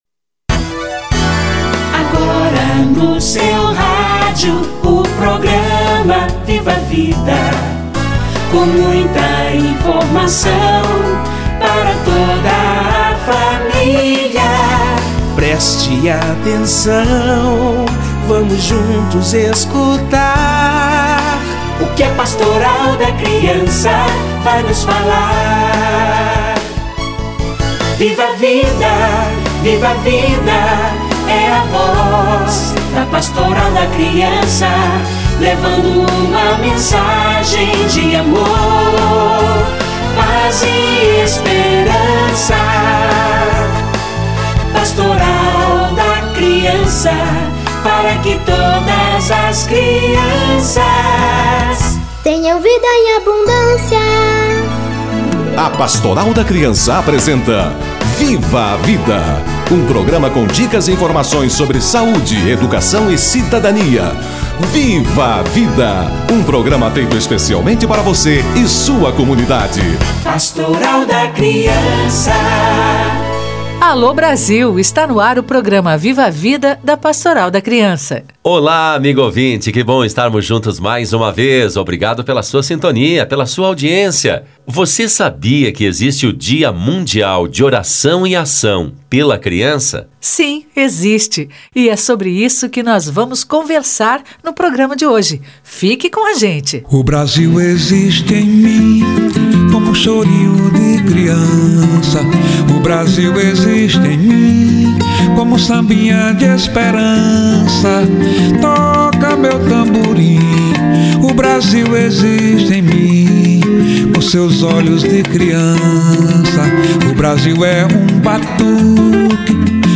Oração e Ação pela Criança - Entrevista